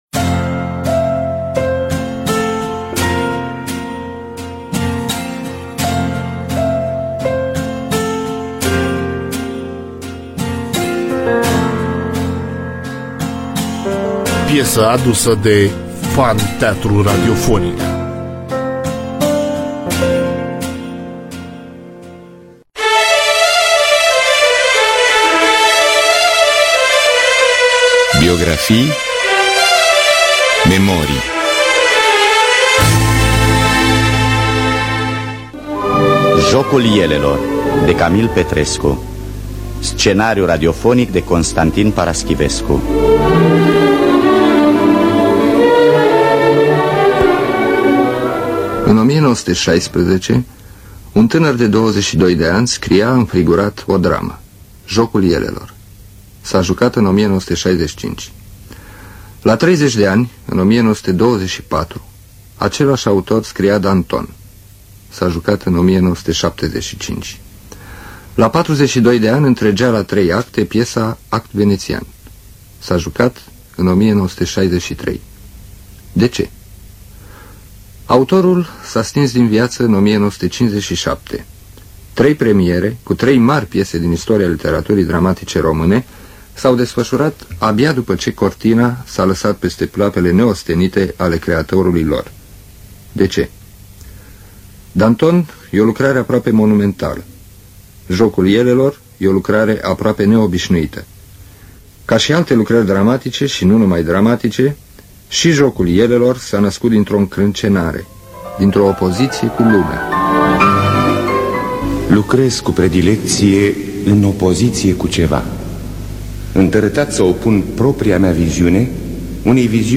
Scenariu radiofonic de Constantin Paraschivescu.